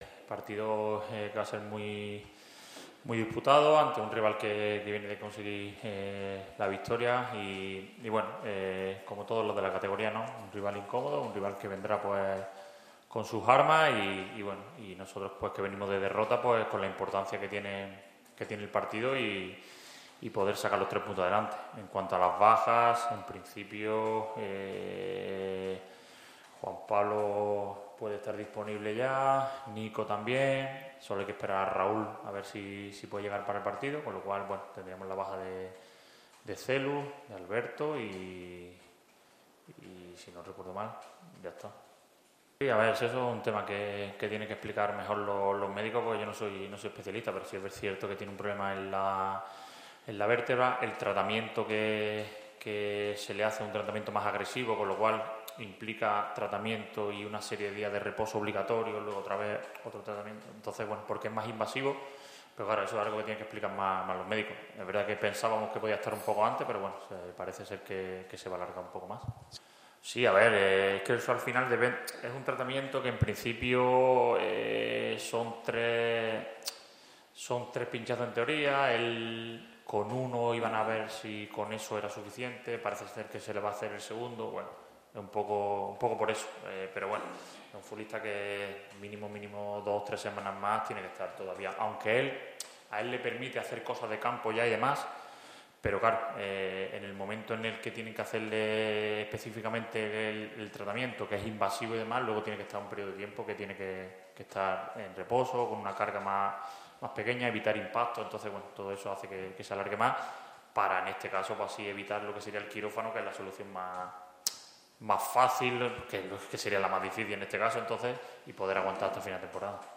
Aquí puedes oír, íntegramente, la comparecencia de prensa del técnico albiazul: